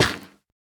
Minecraft Version Minecraft Version 25w18a Latest Release | Latest Snapshot 25w18a / assets / minecraft / sounds / block / nylium / break1.ogg Compare With Compare With Latest Release | Latest Snapshot